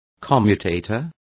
Complete with pronunciation of the translation of commutators.